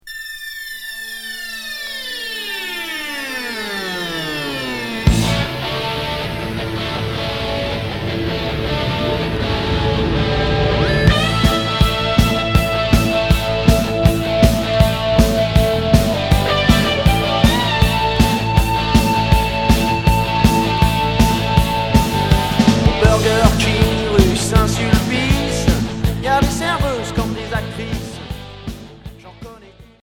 Rock hard Unique 45t retour à l'accueil